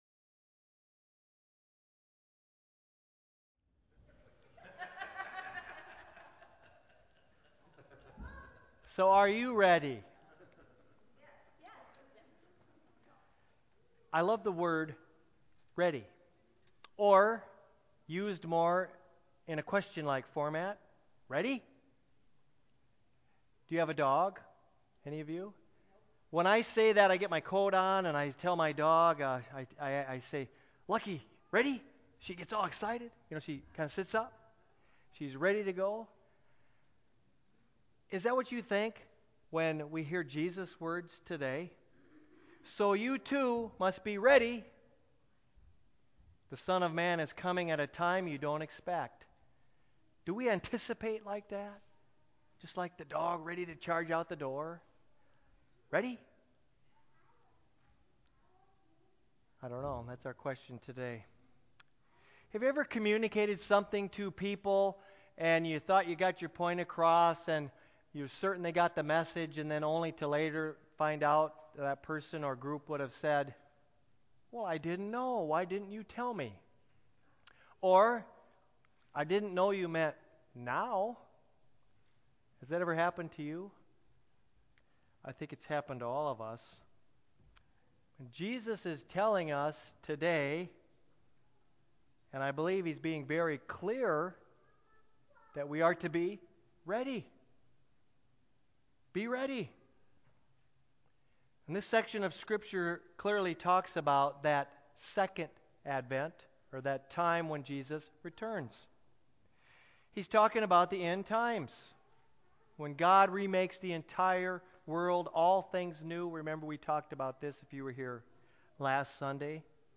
12.1.19-sermon-CD.mp3